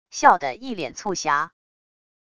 笑的一脸促狭wav音频生成系统WAV Audio Player